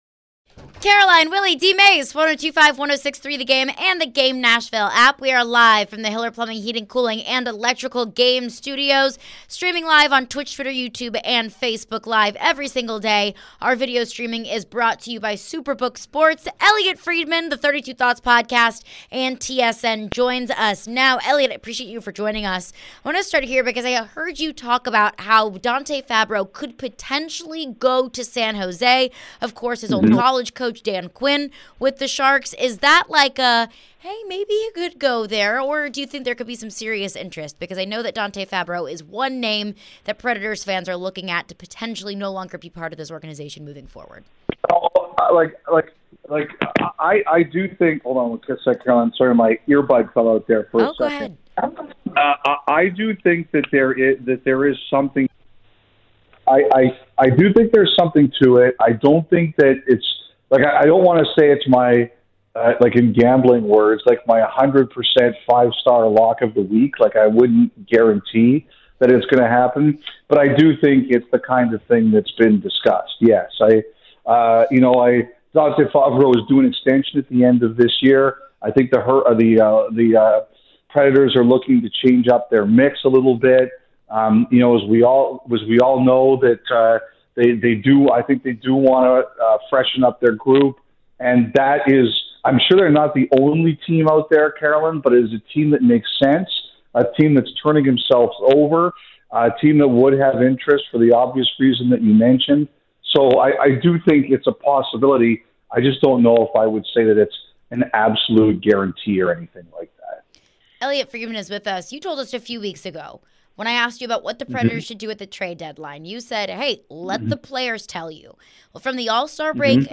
Elliotte Friedman Interview (2-23-23)